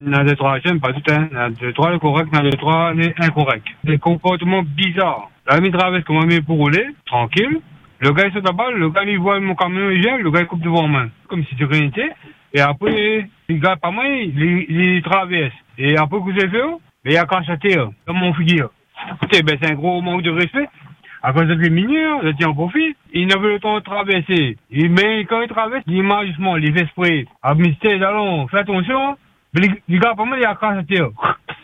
Podcast – Jeunes et manque de respect : un habitant témoigne
Certains comportements de jeunes surprennent et interrogent. Un habitant nous partage une anecdote qui l’a marqué, mettant en lumière ce qu’il perçoit comme un manque de respect dans le quotidien.
Pour lui, ces attitudes révèlent une incompréhension face aux règles de vie en communauté, et il a souhaité partager son ressenti sur notre antenne.